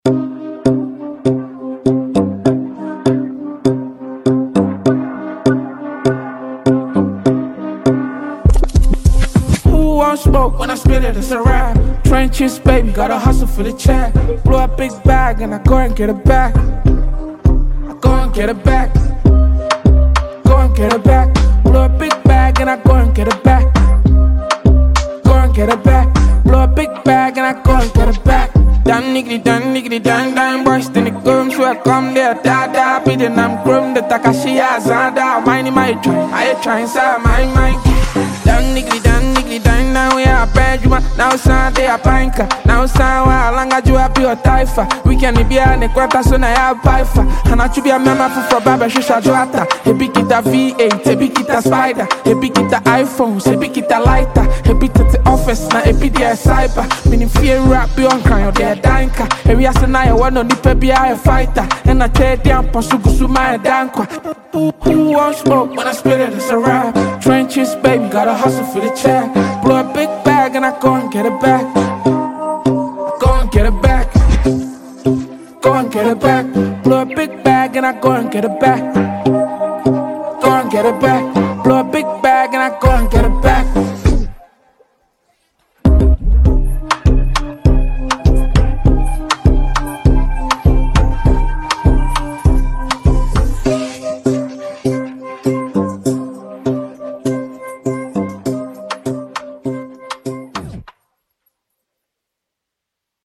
Ghanaian rap talent
charged-up new single